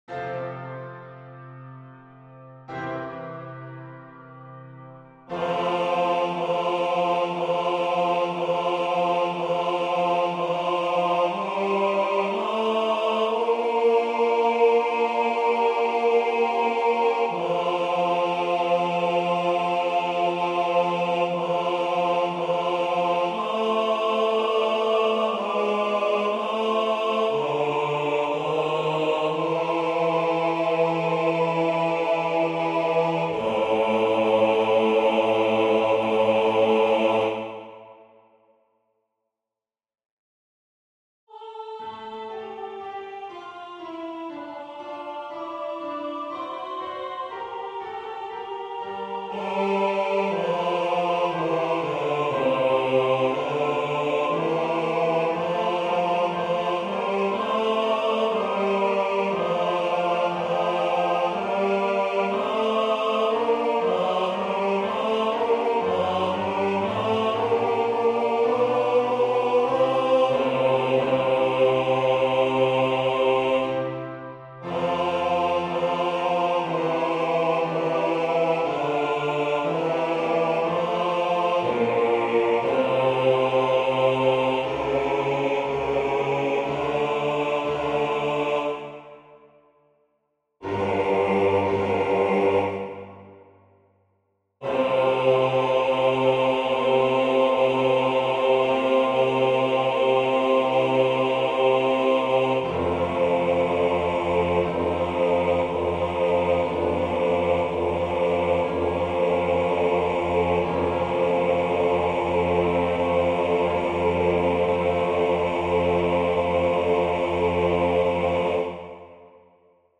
FF:HV_15b Collegium musicum - mužský sbor
Ceska_pisen_1-Bassi.mp3